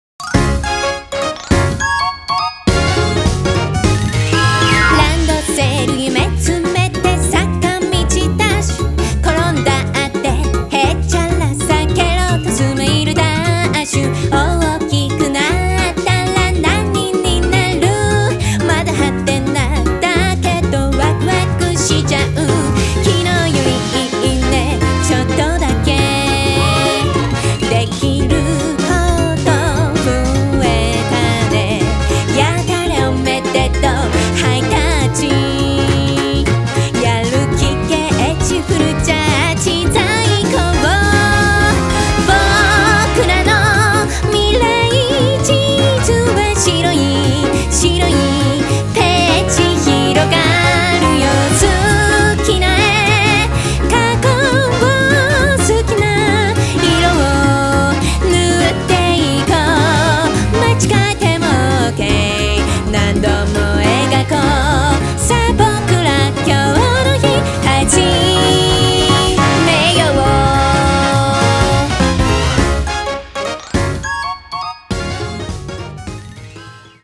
オリジナル版は、思わず体が動いてしまうようなおもちゃジャズの楽しいスウィングサウンド。
シンガーソングライター